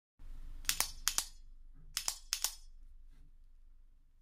The matte plastic push mechanism is integrated with the plastic clip, setting aside a black LAMY logo (actually).
Feel the force of the LAMY logo